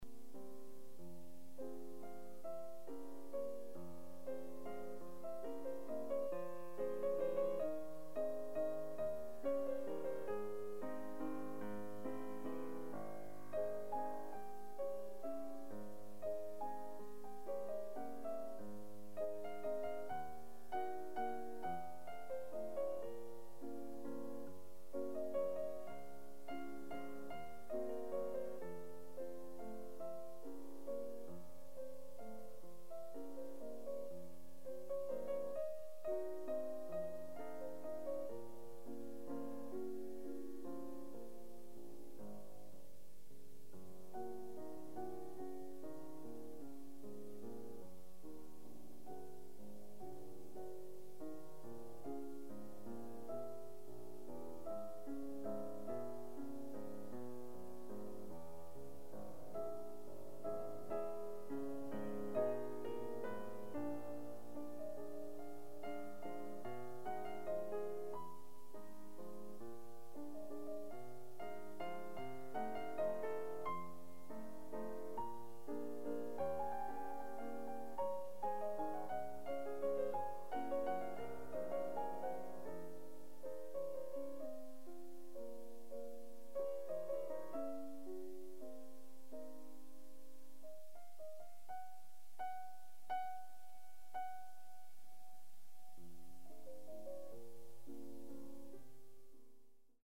Soloist
Recorded September 20, 1973 in the Ed Landreth Hall, Texas Christian University, Fort Worth, Texas
Ballades (Instrumental music)
Suites (Piano)
performed music